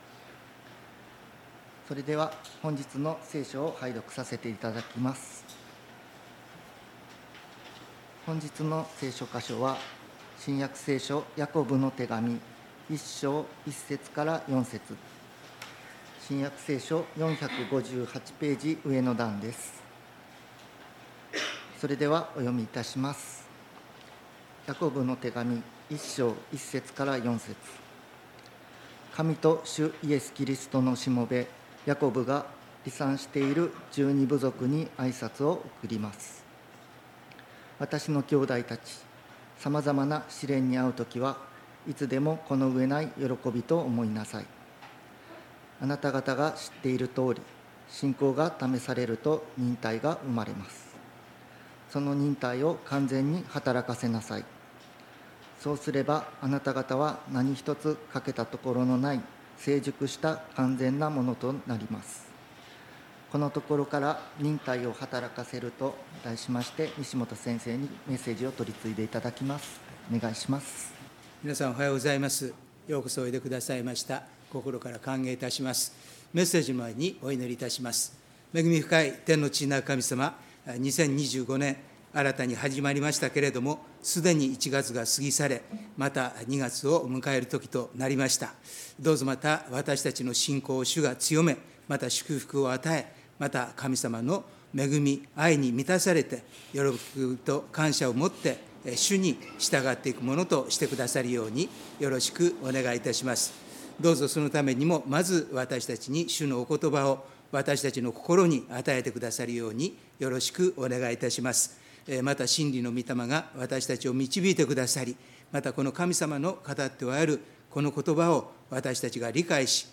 礼拝メッセージ「忍耐を働かせる」│日本イエス・キリスト教団 柏 原 教 会